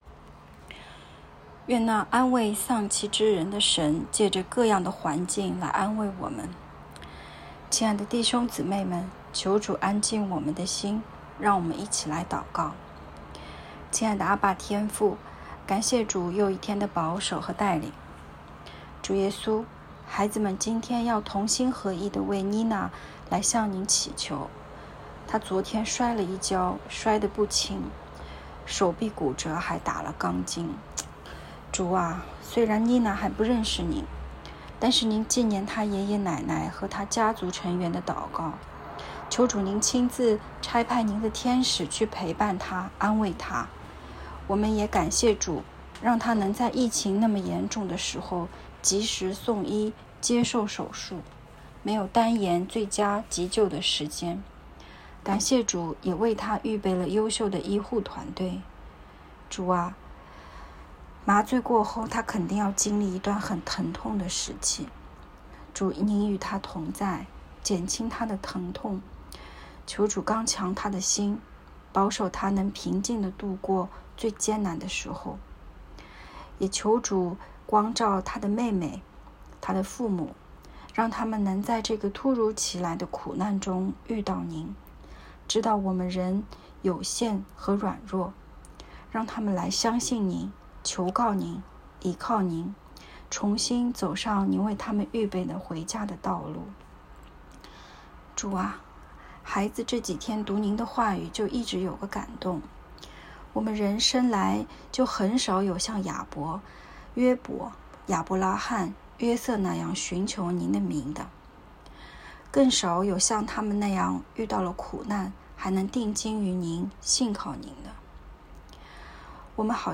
✨晚祷时间✨7月22日（周五）